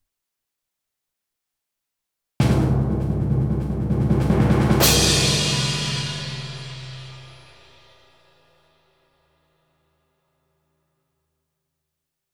drum-roll-please-24b.wav